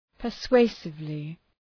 Shkrimi fonetik {pər’sweısıvlı}